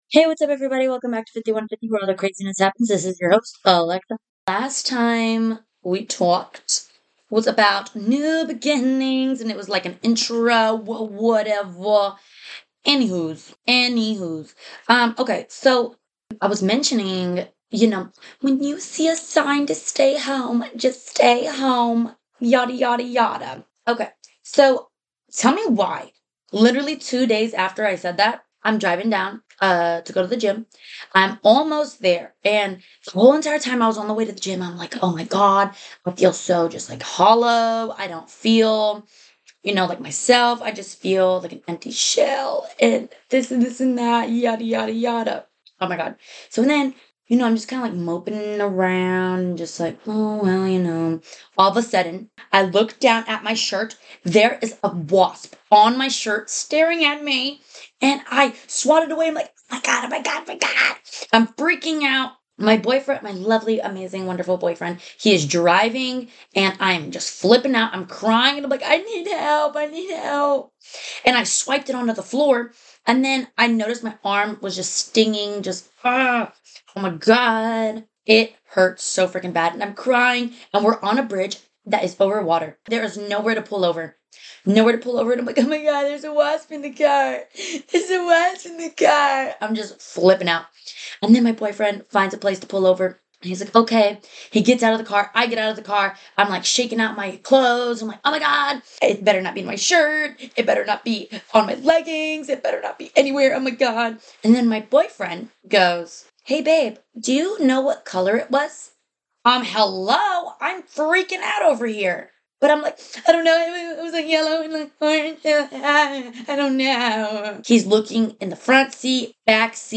I discuss the craziness of humbling experiences of life and in laws. Featuring a very special guest